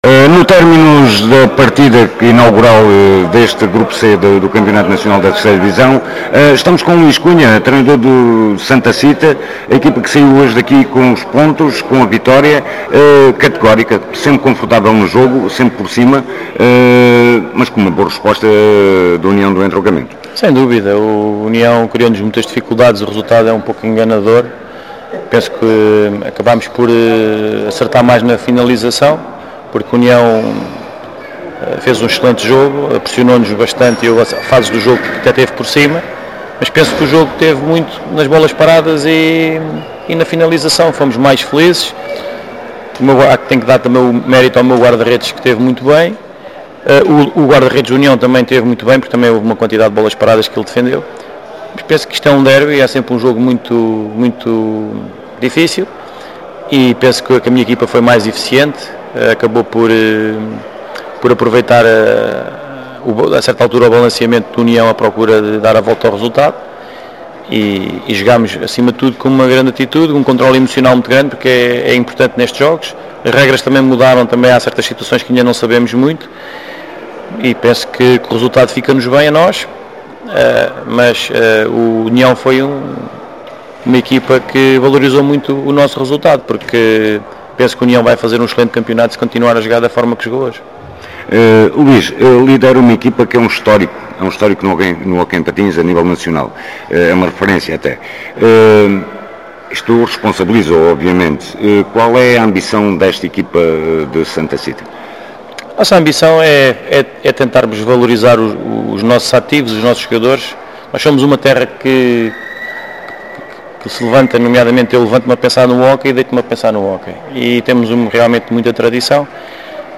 No final estivemos à conversa com ambos os treinadores: